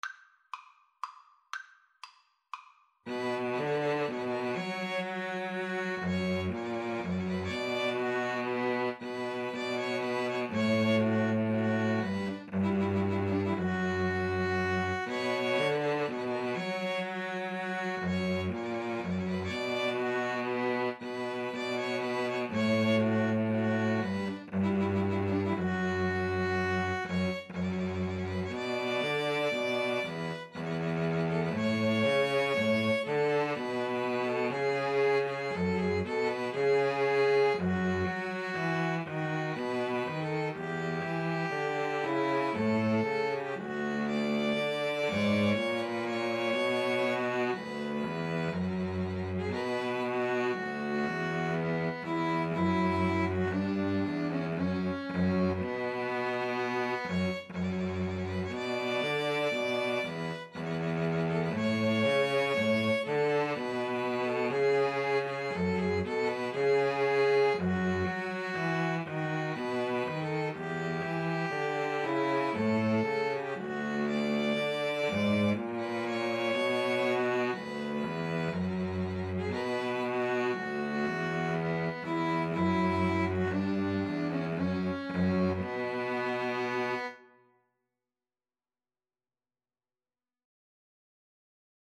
Vivace (View more music marked Vivace)
String trio  (View more Intermediate String trio Music)
Classical (View more Classical String trio Music)